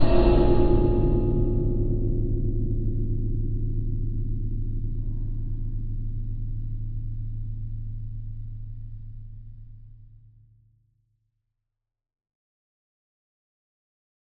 cave1.ogg